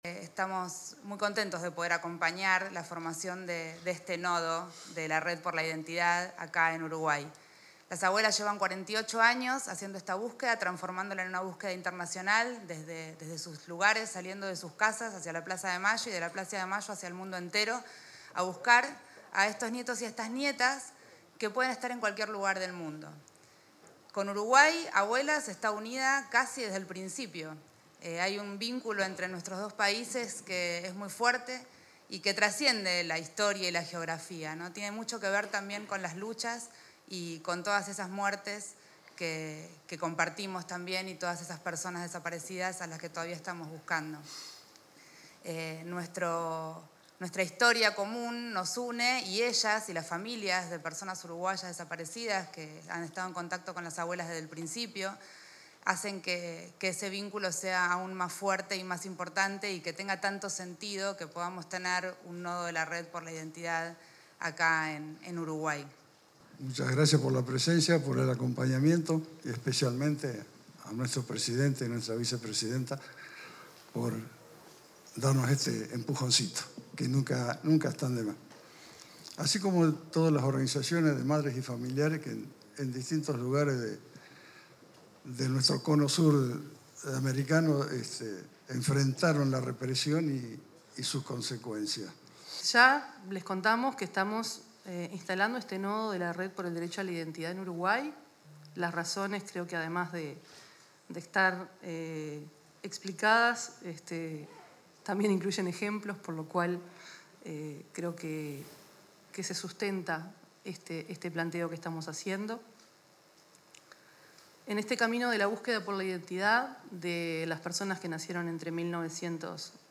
se expresaron en el lanzamiento de la campaña de búsqueda de nietos desaparecidos en Uruguay y el comienzo de las actividades de la Red por el Derecho a la Identidad-Nodo Uruguay.
oratoria.mp3